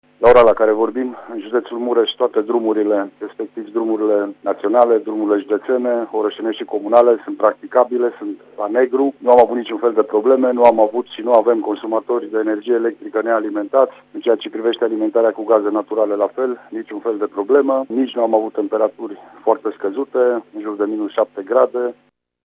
La această oră nu există drumuri blocate, nici localități fără curent sau gaz, dă asigurări prefectul județului Mureș, Lucian Goga:
prefect-situatie.mp3